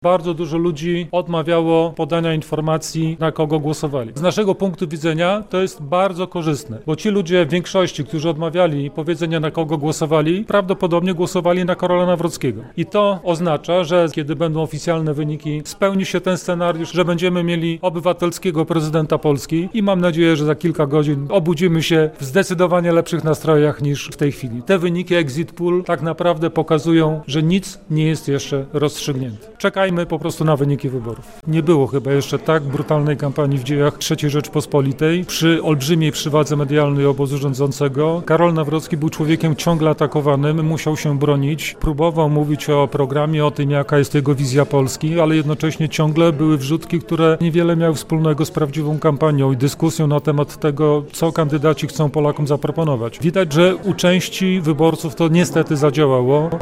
Sondażowe wyniki komentuje Dariusz Piontkowski | Pobierz plik.